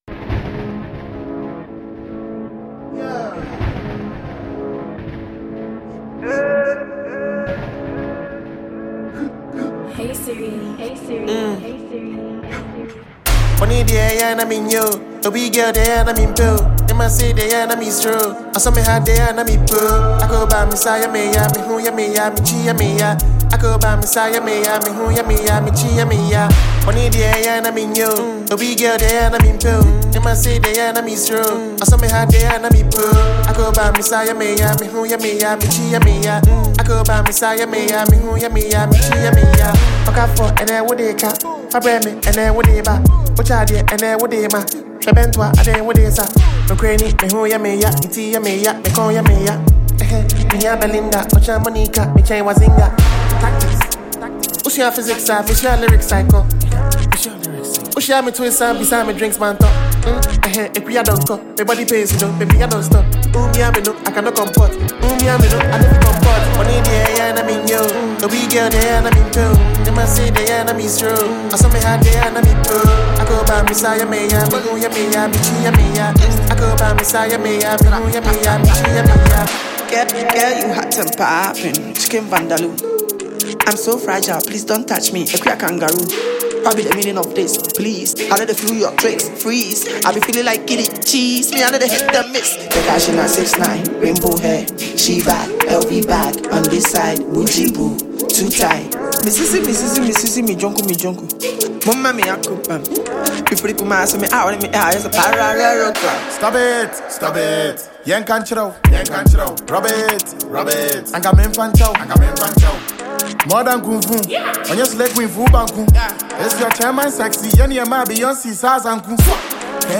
Ghana Music
Ghanaian indigenous rapper